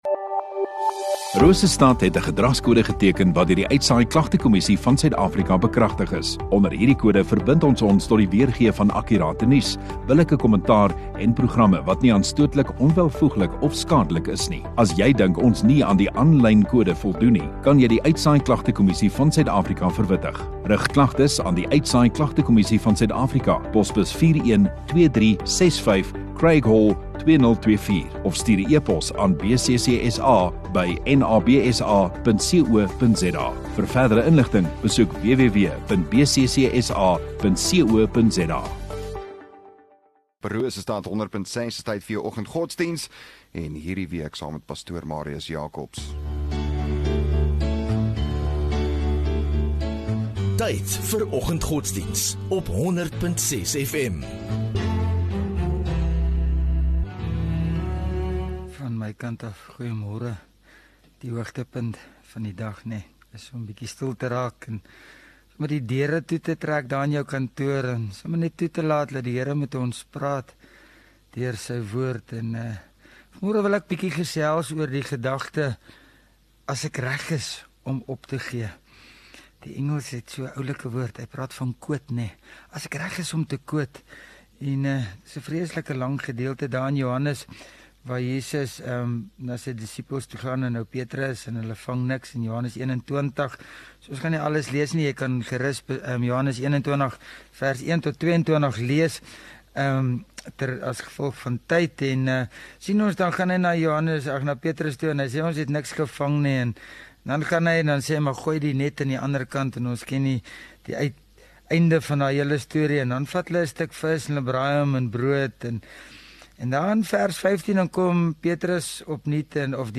26 Aug Dinsdag Oggenddiens